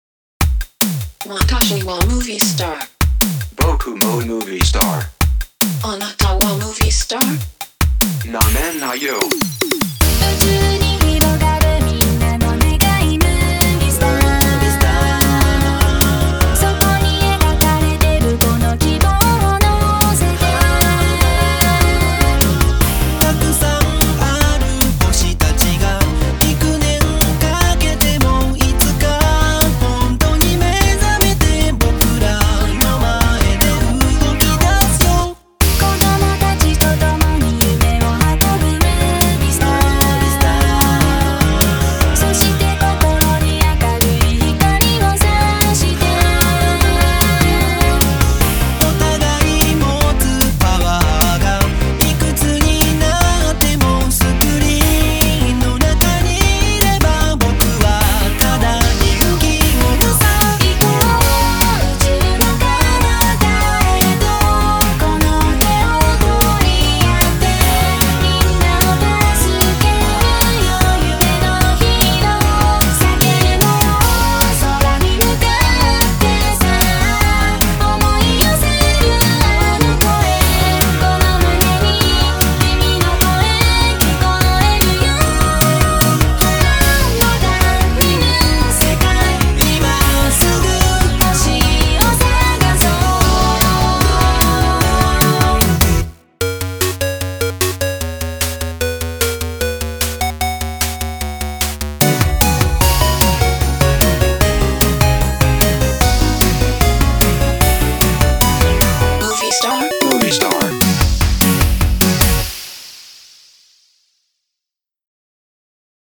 BPM150
I liked the chippy pop feel of this song.